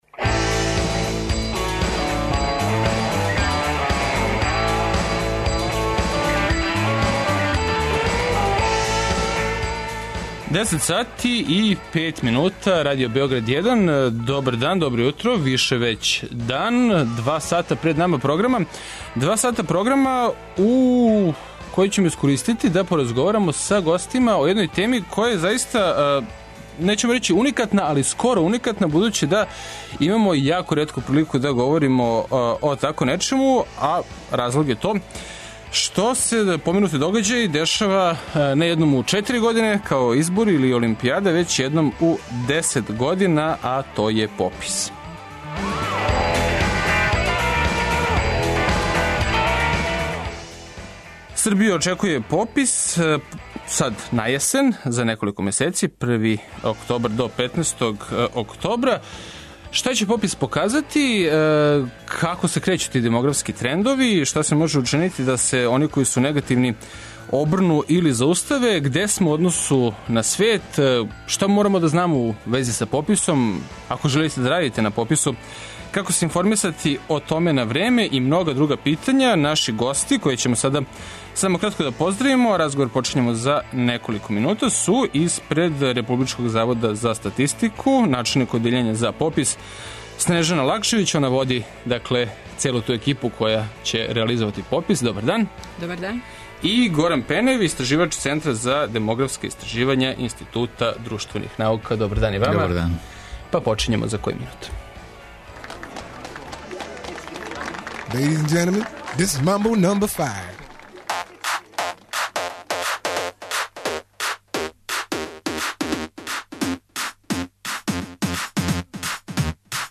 Радио Београд 1, 15.30 Формулар за попис бр. 1 Формулар за попис бр. 2